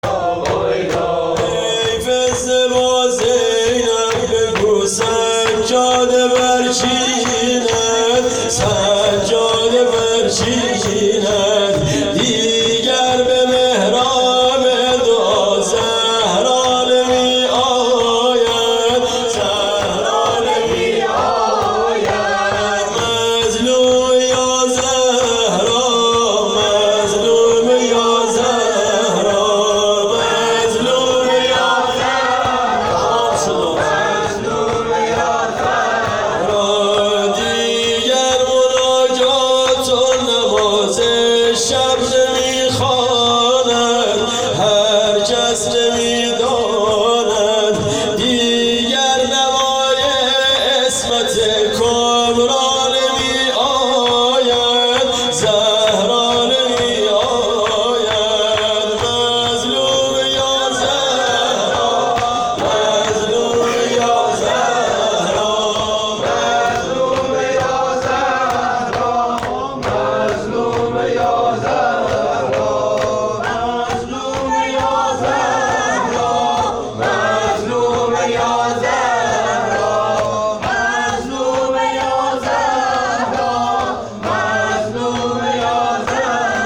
هیئت محبان المهدی(عج)آمل
شور
دهه دوم فاطمیه 97_شب چهارم